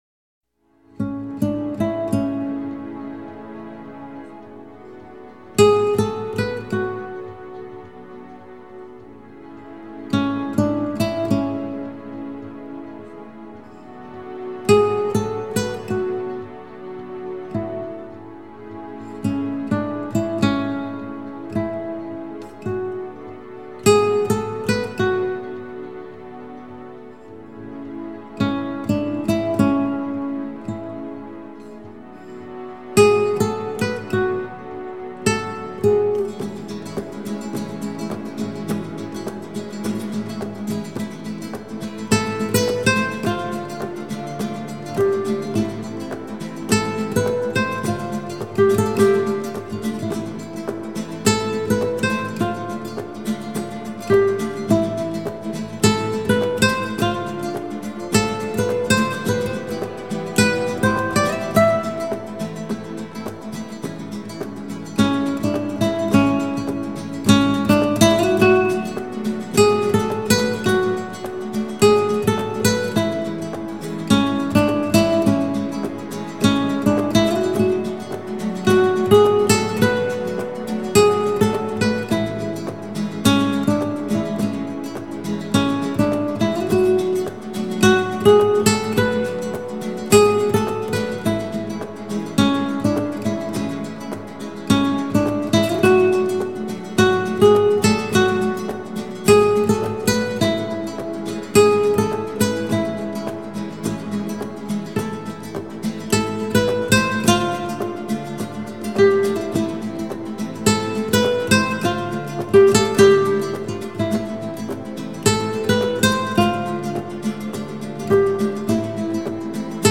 لایتموسیقی
اثری زیبا و خاص و فوق العاده آرامبخش
نوع آهنگ: لایت]